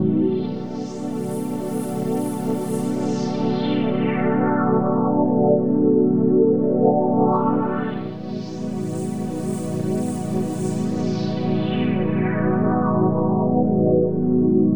Pad_130_B.wav